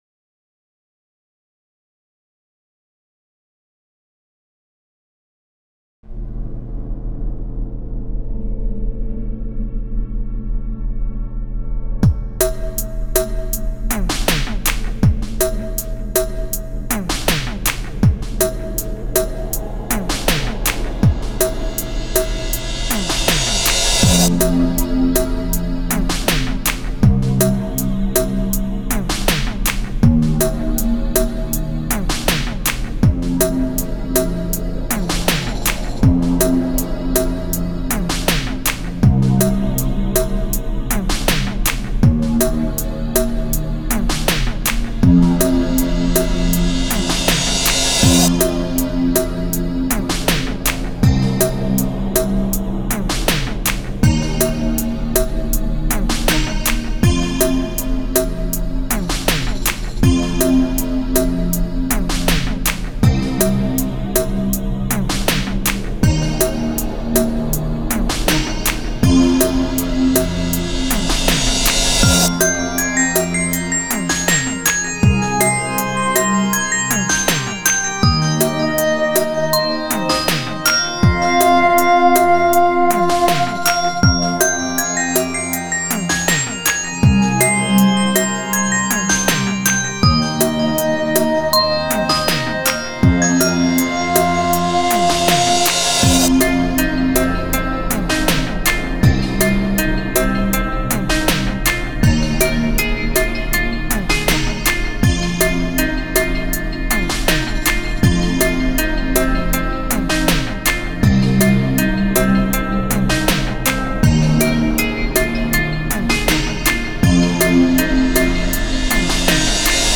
音もう少しマシなはずなのに。
（最初5秒ほど無音なのは　仕　様　で　す　ｗ　）
リバーブ遠慮せずたらふくかけてみましたｗ
金物のちゃんとしたサンプルほしいなあ（；；）